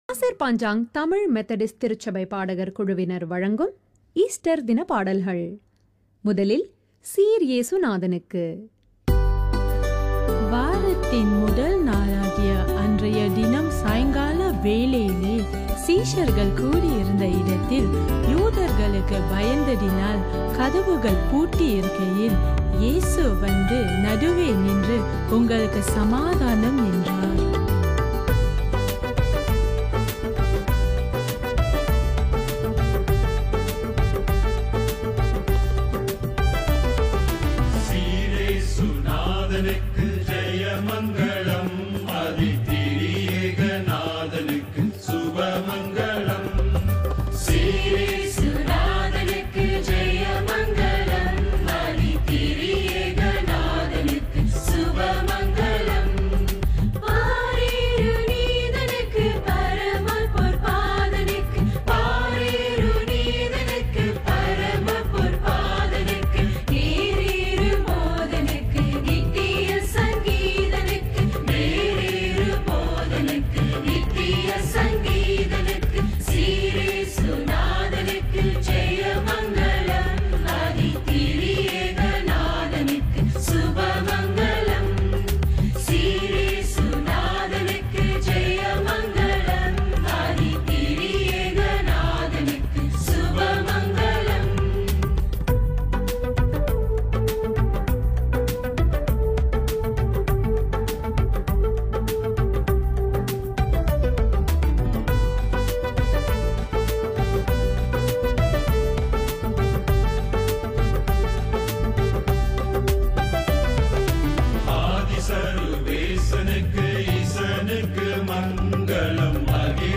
Resurrection Sunday Radio recording